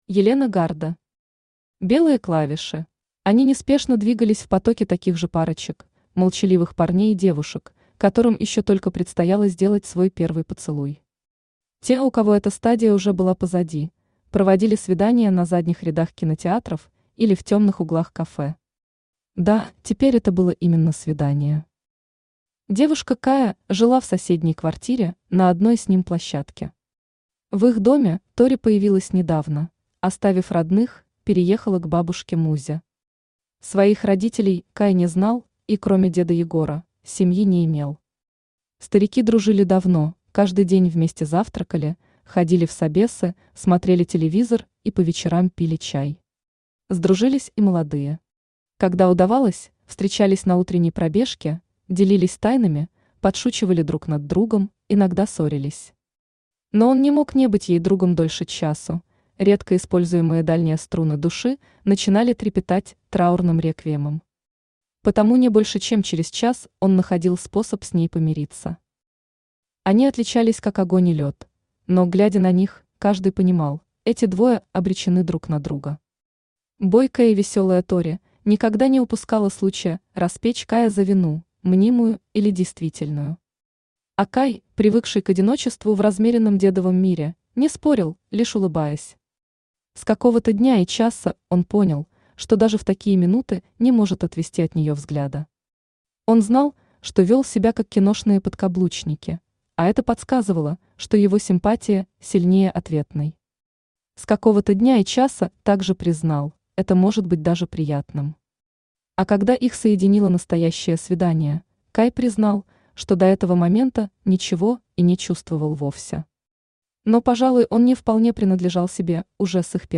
Aудиокнига Белые клавиши Автор Елена Гарда Читает аудиокнигу Авточтец ЛитРес.